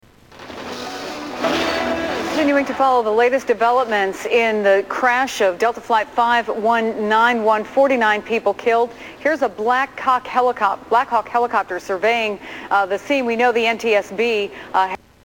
Tags: Media Freudian Slips News Newscasters Funny